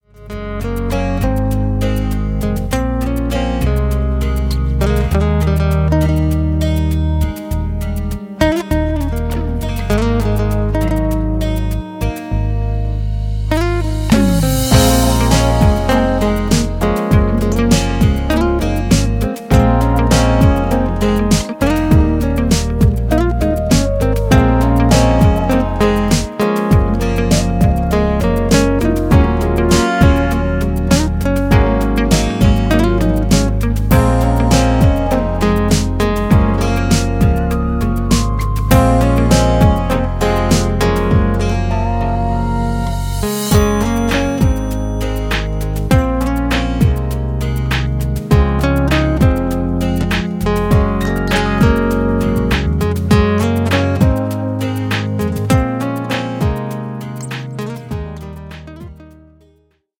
Instrumental
Dabei greift er nicht nur auf die E-Gitarre zurück
akustischen Gitarre